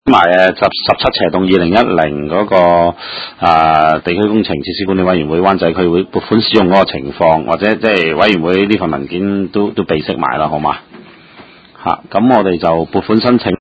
地區工程及設施管理委員會第十五次會議
灣仔民政事務處區議會會議室